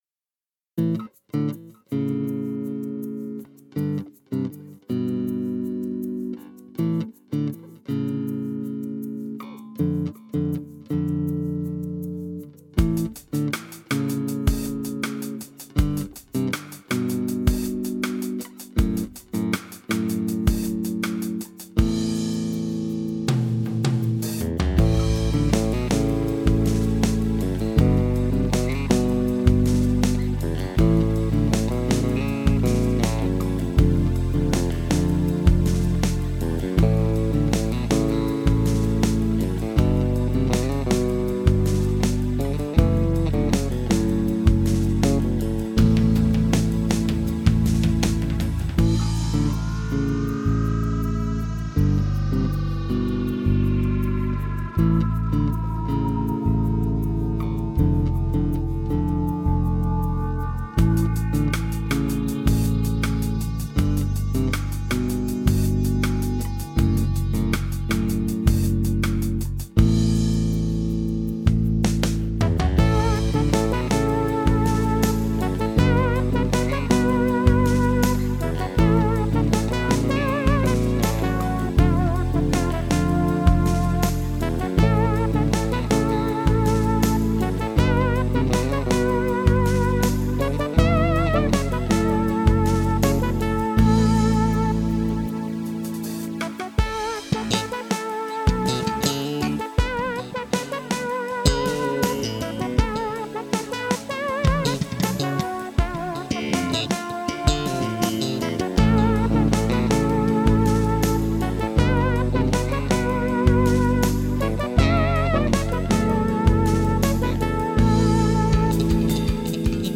Orange Crush 25BX + SM57 743,1 ��
Orange Crush 25BX line out 2,94 ��